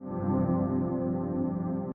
Attack: 100%
Decay: 100%
Sustain 50%
What will happen is that initially, the volume will slope up to attack 100%, and then gradually decrease (decay 100%) until it reaches the 50% sustain level.